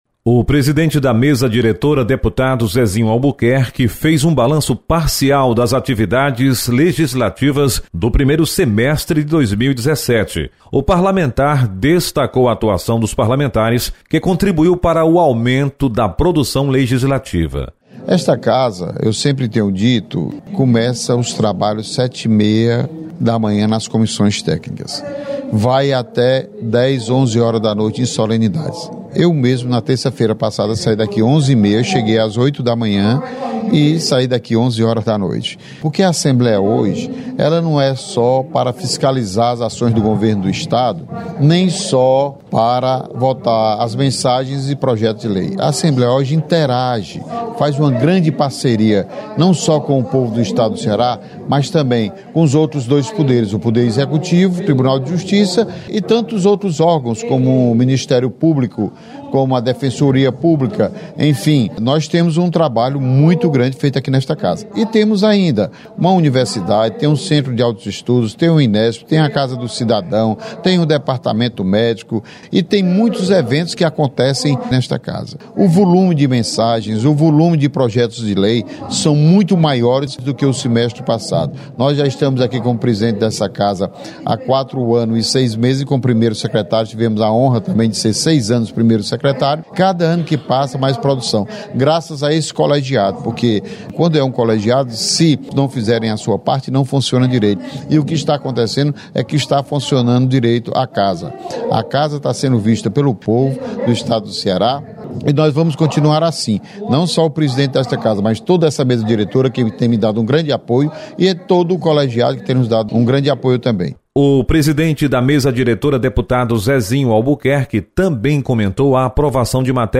Presidente da Assembleia Legislativa, deputado Zezinho Albuquerque apresenta balanço do  primeiro semestre. Repórter